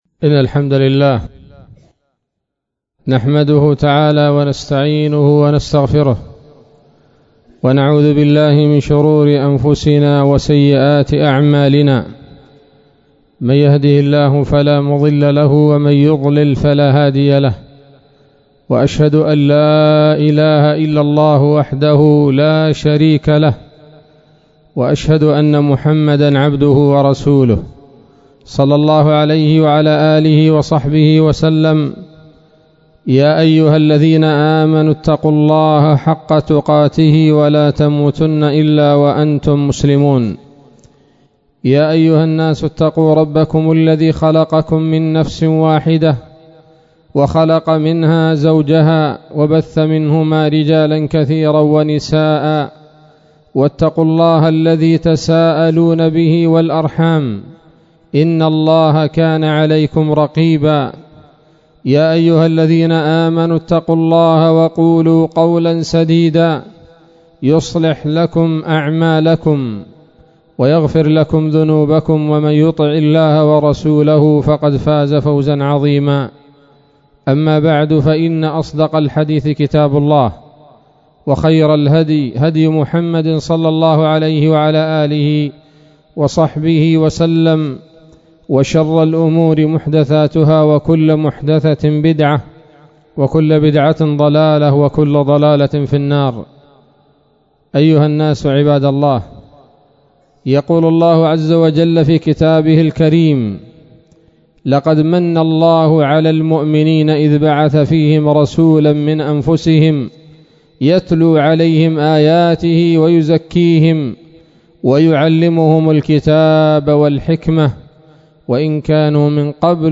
خطبة جمعة بعنوان